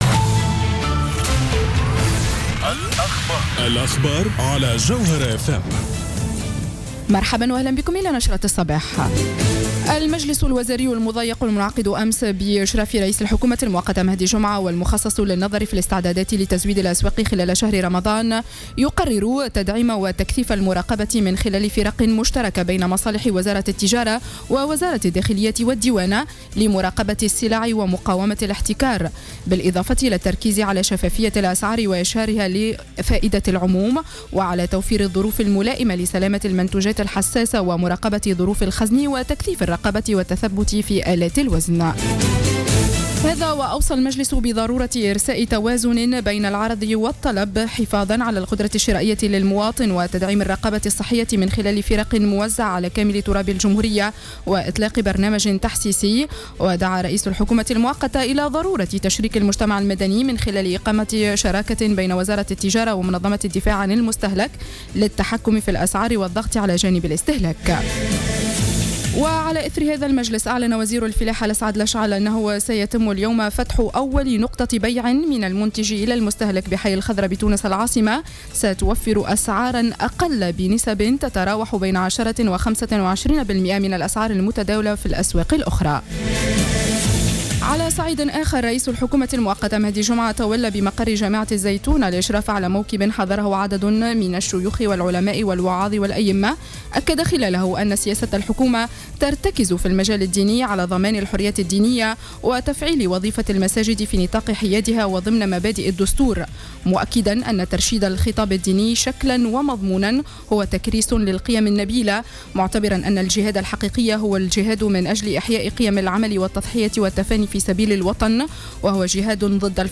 نشرة أخبار السابعة صباحا ليوم الجمعة 27-06-14